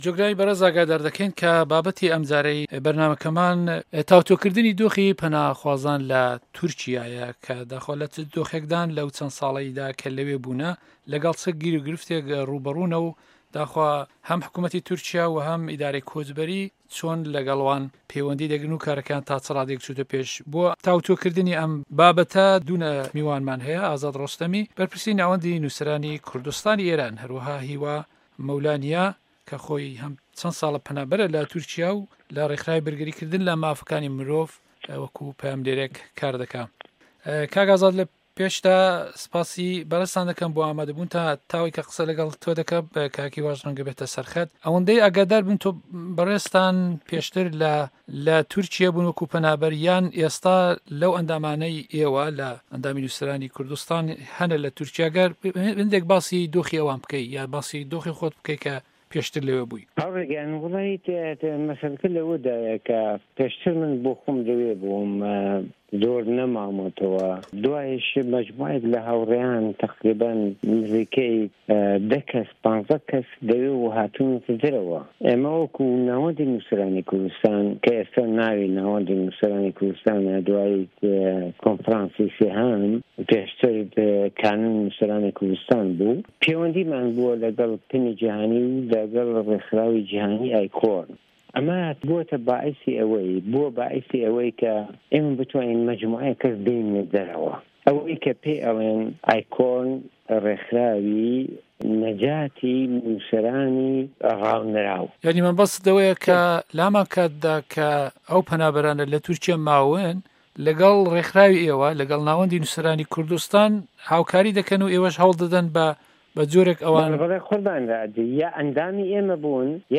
مێزگرد